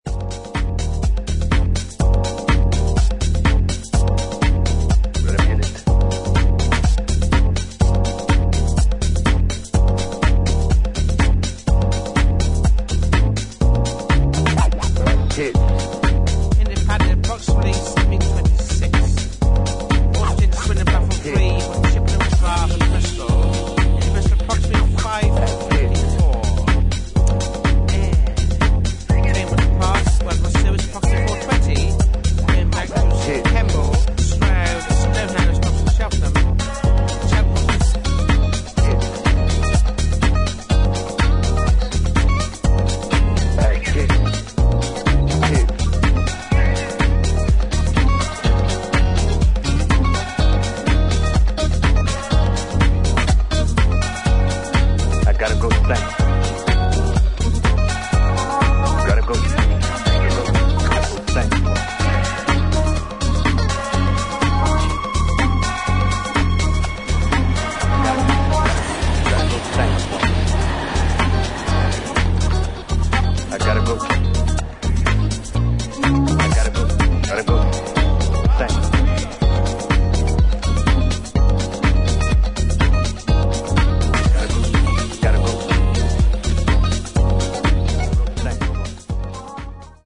抜けの良いタイトな4つ打ちにダビーな生楽器の音色が絡む、ディープでファンキーなウェストコースト・スタイルハウス。